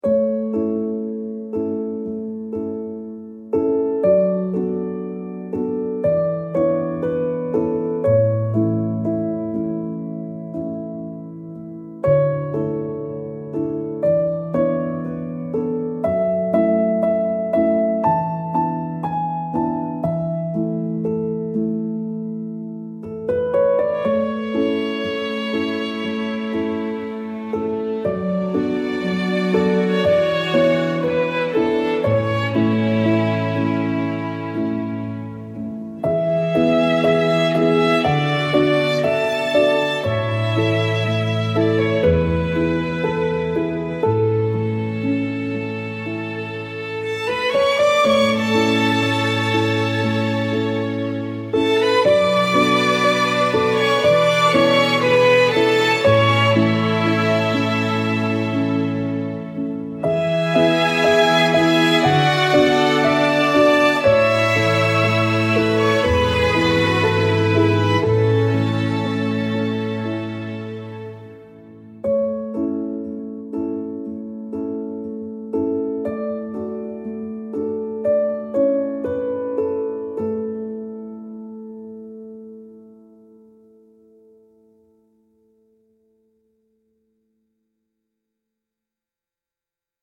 soft romantic piano and strings with warm candlelight ambiance